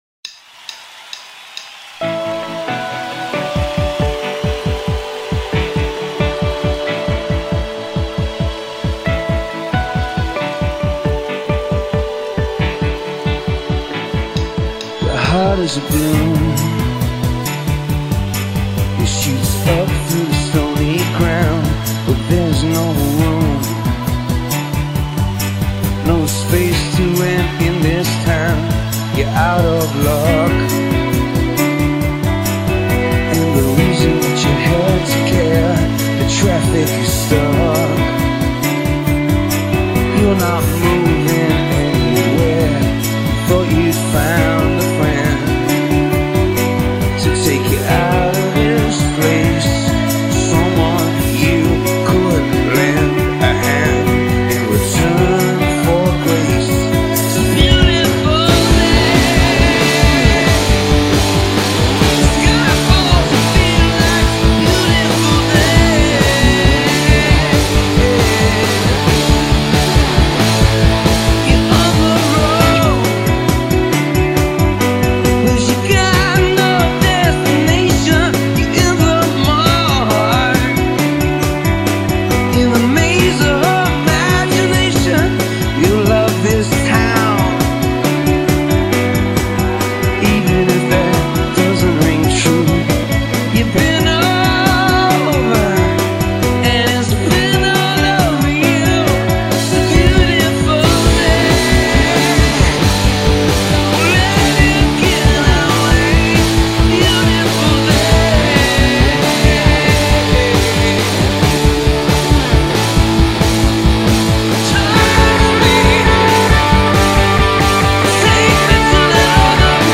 Multi-track recording